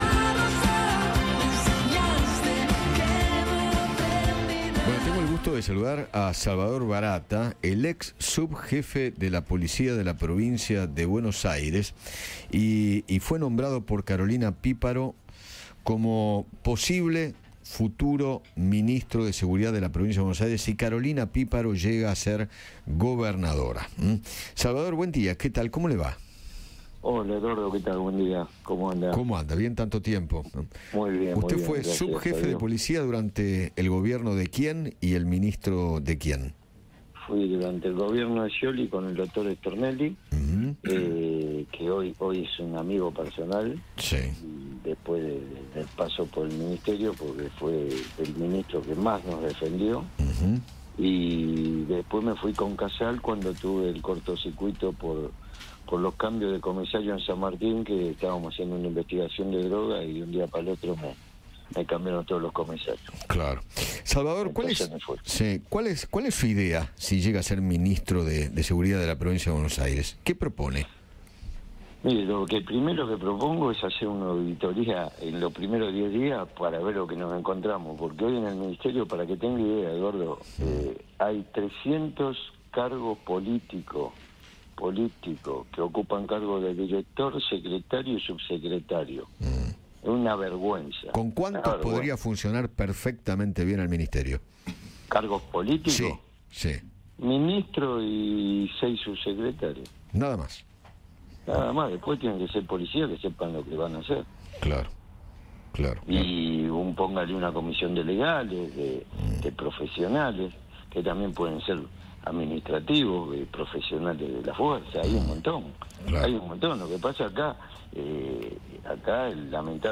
Salvador Baratta, ex subjefe de Policía de PBA, conversó con Eduardo Feinmann, después de que Carolina Píparo lo confirmara como su ministro de Seguridad en caso de ser elegida como gobernadora de la provincia.